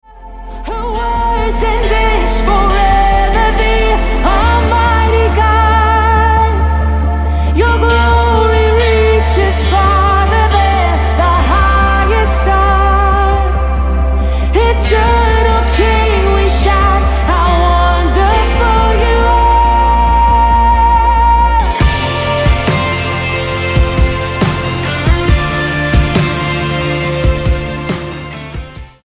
STYLE: Pop
characteristically powerful vocals take centre stage here